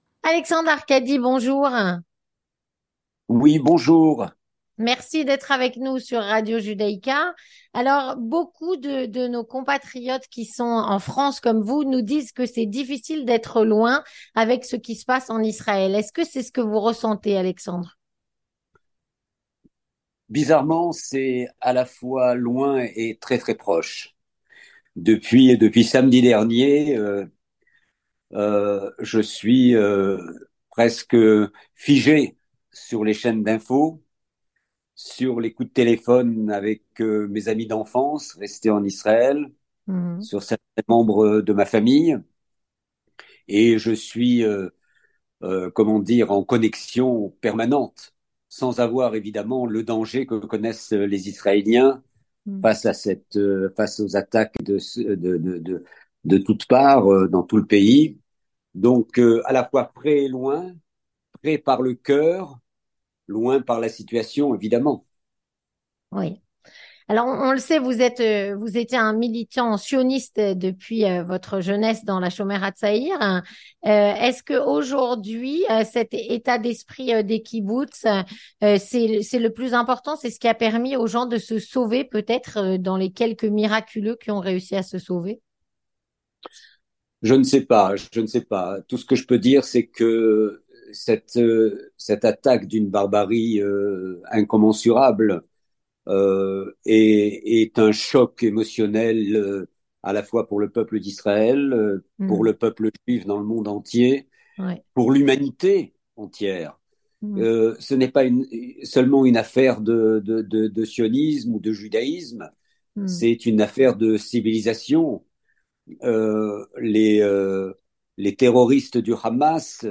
Vue de France - Entretien sur la guerre entre Israël et le Hamas. Avec Alexandre Arcady (18/10/2023)
Avec Alexandre Arcady, réalisateur engagé aux côtés d’Israël depuis des années